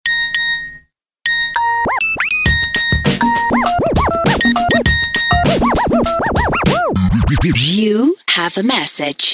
SMS-мелодии [24]
Азбука Морзе